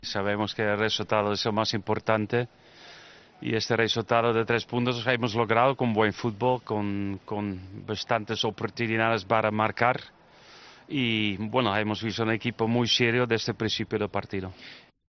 AUDIO: El entrenador del Barça analizó en Movistar la victoria de su equipo sobre el Valladolid (0-3).